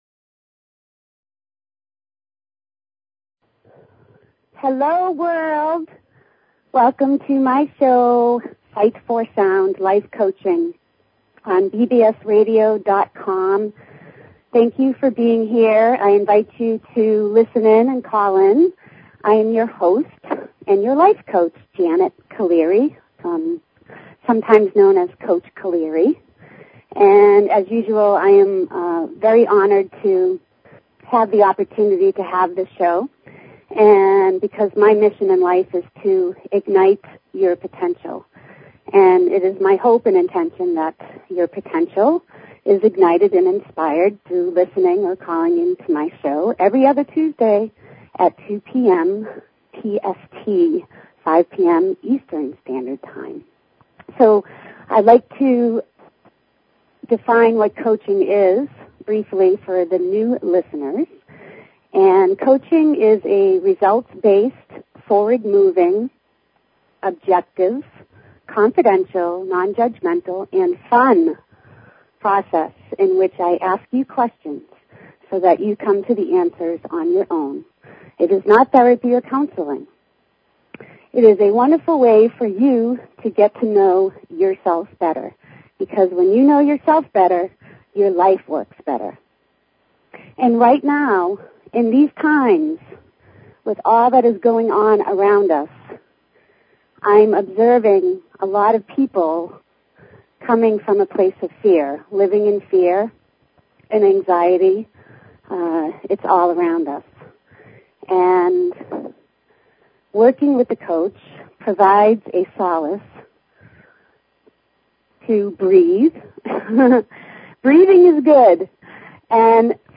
Talk Show Episode, Audio Podcast, Sight_for_Sound and Courtesy of BBS Radio on , show guests , about , categorized as
She encourages you to call in and ask questions or share thoughts!!!